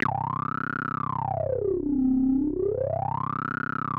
C1_wasp_lead_1.wav